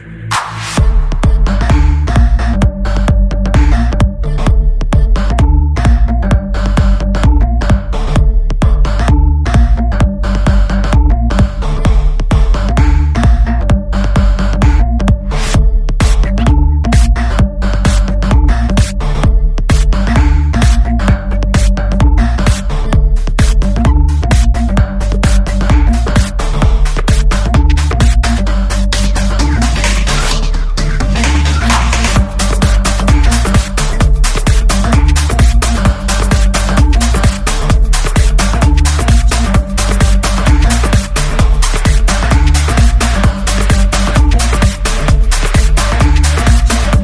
zvon.mp3